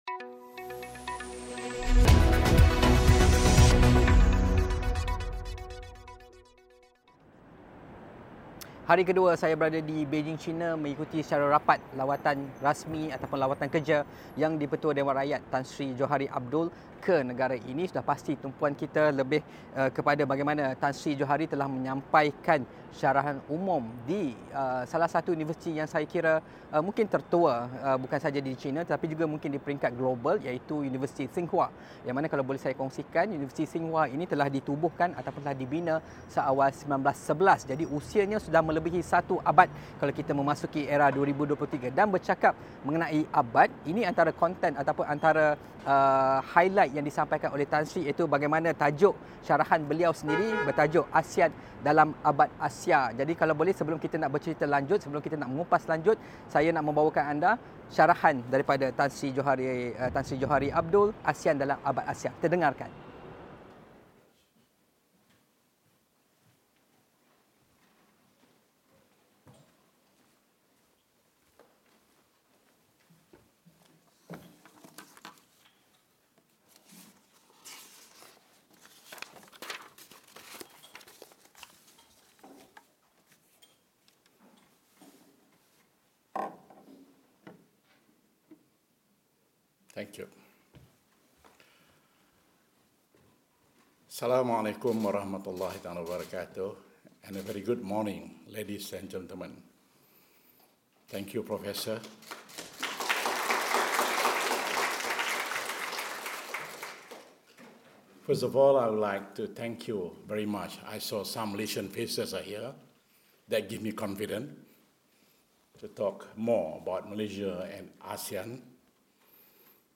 Yang Dipertua Dewan Rakyat, Tan Sri Johari Abdul menyampaikan syarahan umum bertajuk 'Asean Dalam Abad Asia' di Universiti Tsinghua, Beijing sempena lawatan rasminya ke China bermula Sabtu lalu.